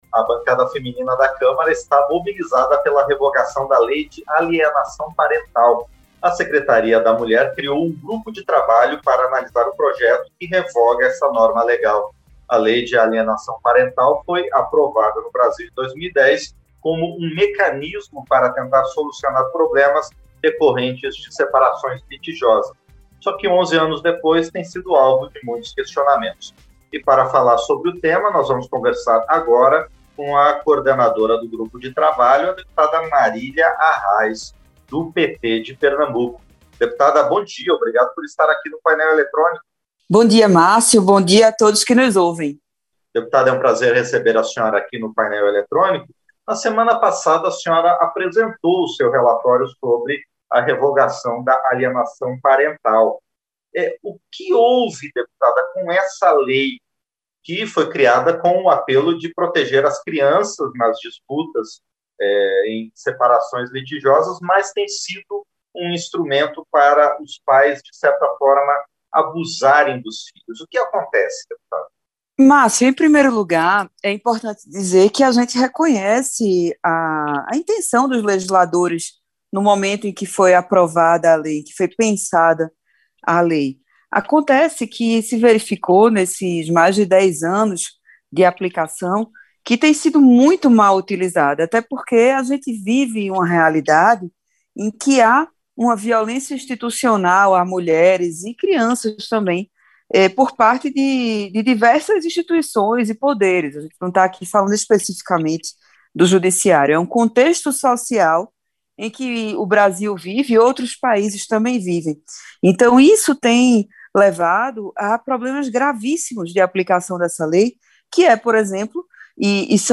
Entrevista - Dep. Marília Arraes (PT-PE)